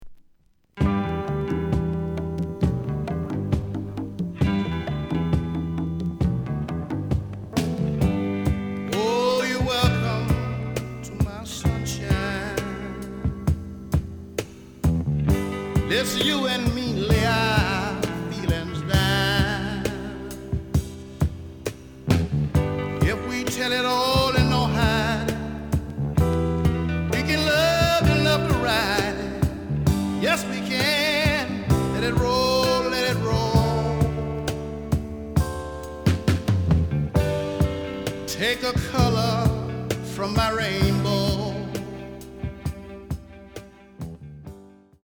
The listen sample is recorded from the actual item.
●Genre: Disco